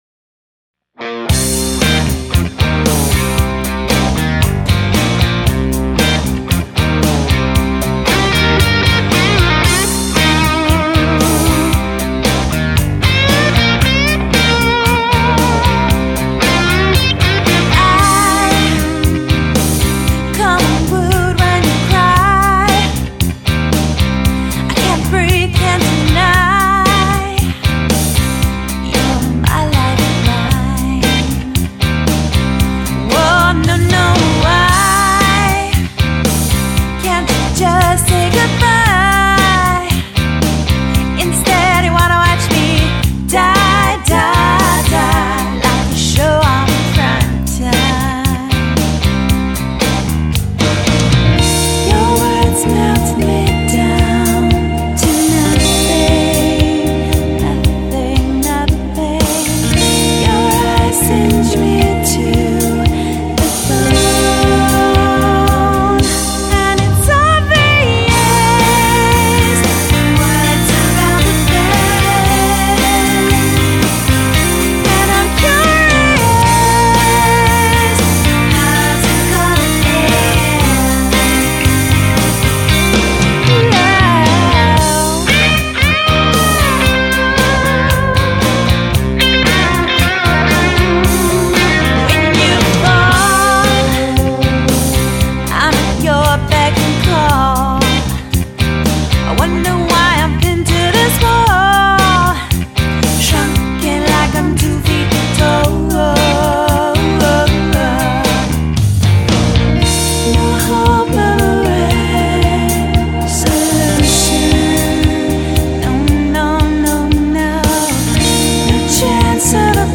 Mid tempo Fmvx/acoustic rock